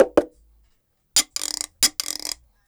89-PERC4.wav